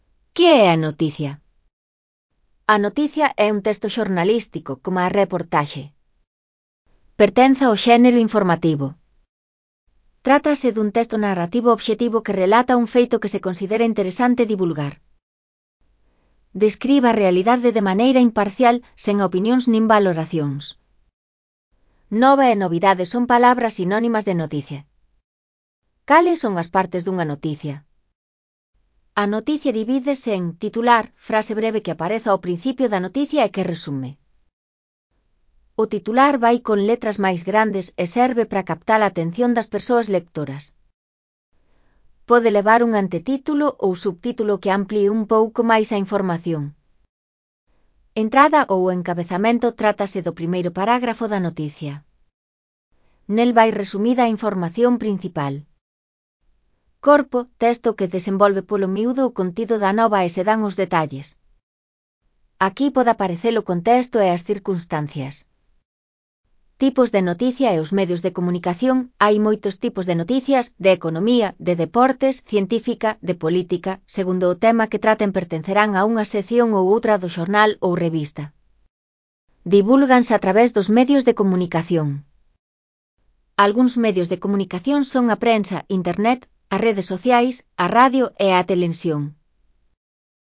Elaboración propia (Proxecto cREAgal) con apoio de IA, voz sintética xerada co modelo Celtia. A noticia (CC BY-NC-SA)